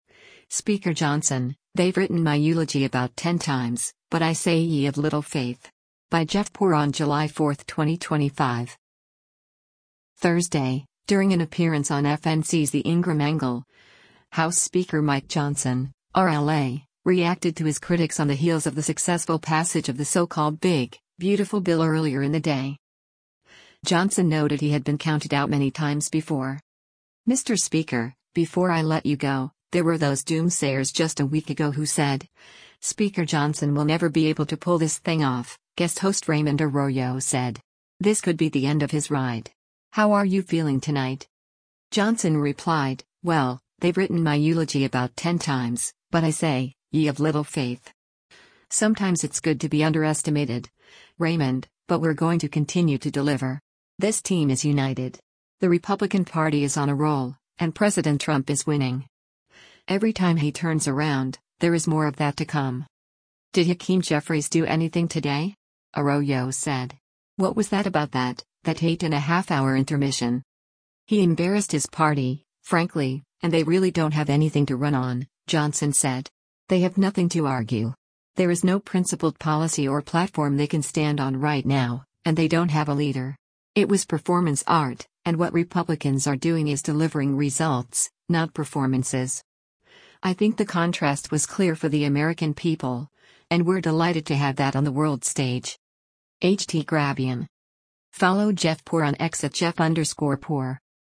Thursday, during an appearance on FNC’s “The Ingraham Angle,” House Speaker Mike Johnson (R-LA) reacted to his critics on the heels of the successful passage of the so-called “Big, Beautiful Bill” earlier in the day.